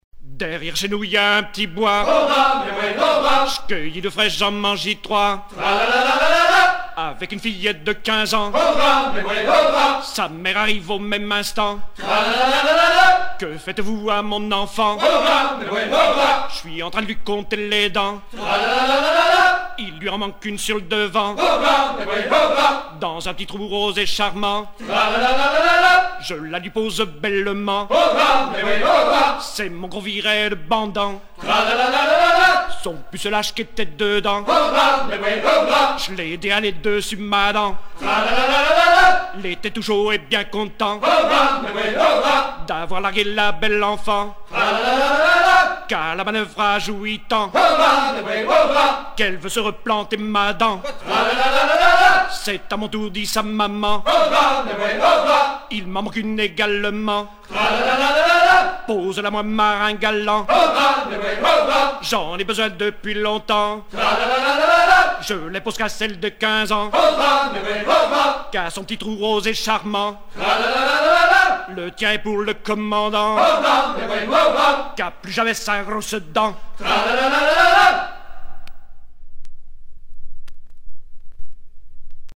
gestuel : à hisser à courir
Genre laisse